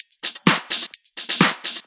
Index of /VEE/VEE Electro Loops 128 BPM
VEE Electro Loop 198.wav